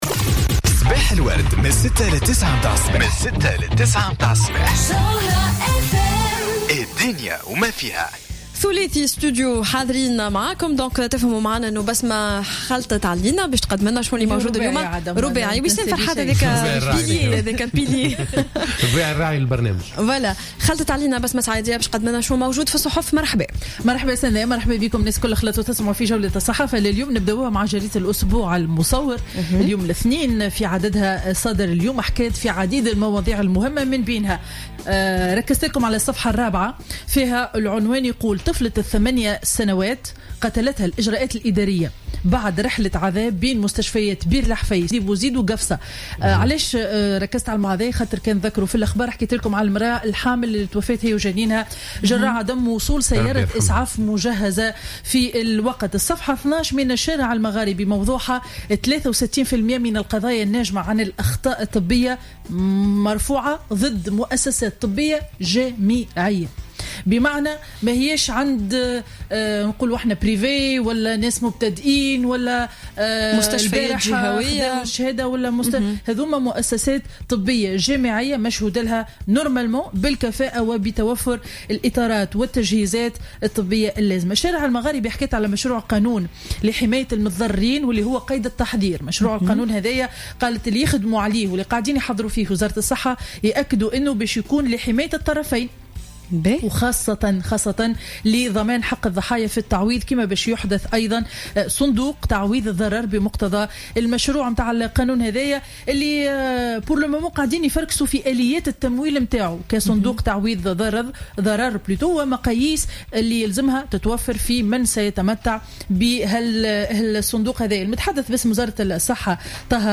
Revue de presse du lundi 22 février 2016